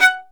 Index of /90_sSampleCDs/Roland - String Master Series/STR_Violin 1-3vb/STR_Vln3 _ marc